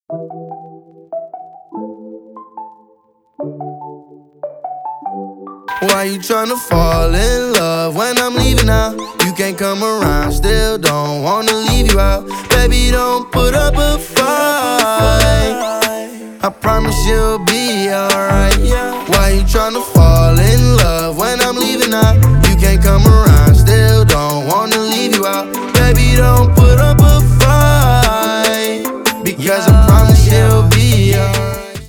Рэп и Хип Хоп
весёлые